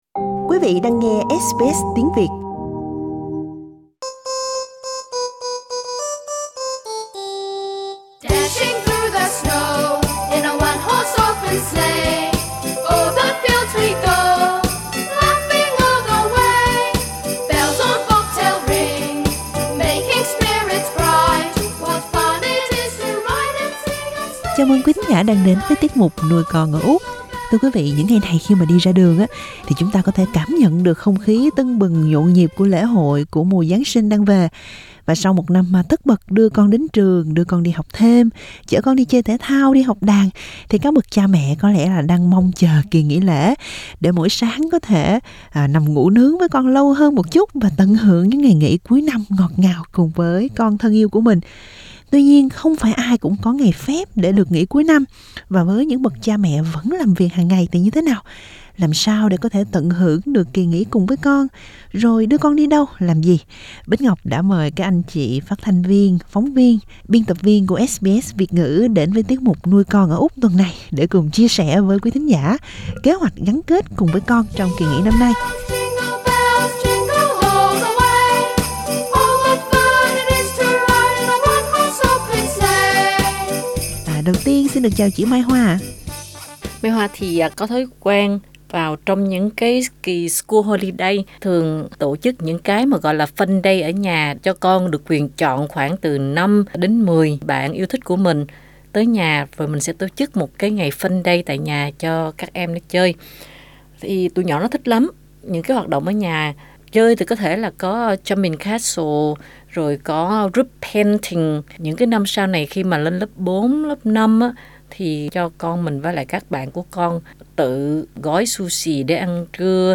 Đây cũng là dịp mà nhiều phụ huynh có kỳ nghỉ phép hàng năm để nghỉ xả hơi và tận hưởng thời gian với con cái. Cùng nghe các phóng viên và phát thanh viên của SBS Việt ngữ chia sẻ họ sẽ làm gì cùng con trong kỳ nghỉ, khi vẫn bận rộn “lên sóng” mỗi ngày.